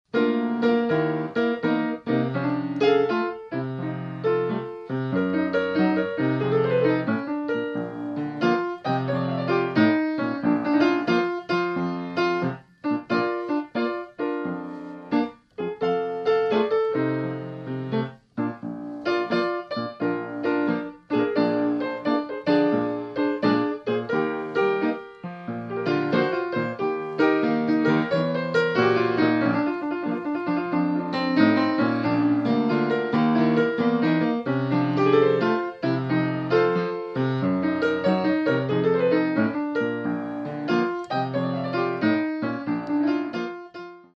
Style: Barrelhouse Piano
Es gibt sogar eine Art Melodie.